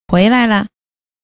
何回聴いても
コエラエラ
に聞こえる